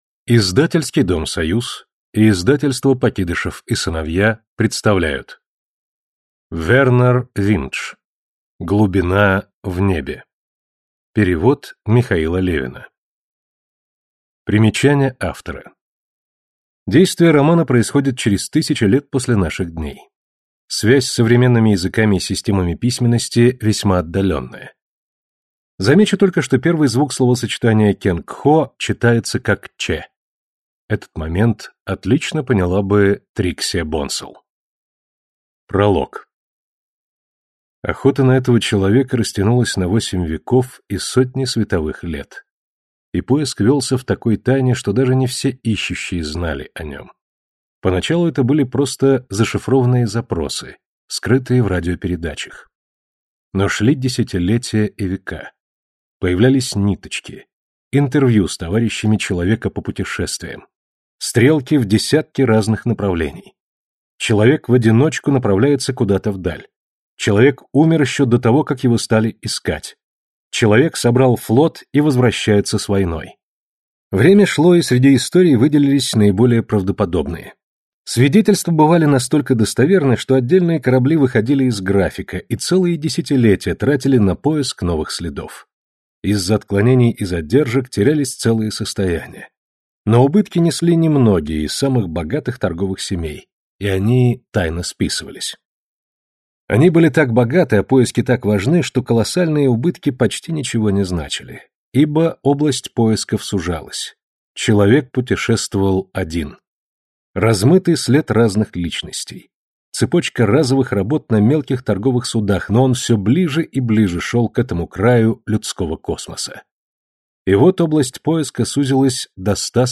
Аудиокнига Глубина в небе | Библиотека аудиокниг
Прослушать и бесплатно скачать фрагмент аудиокниги